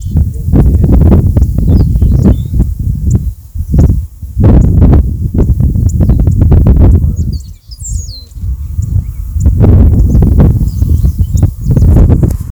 Tordo Amarillo (Xanthopsar flavus)
Nombre en inglés: Saffron-cowled Blackbird
Localidad o área protegida: Departamento Gualeguaychú
Condición: Silvestre
Certeza: Fotografiada, Vocalización Grabada
Tordo-amarillo_1_1.mp3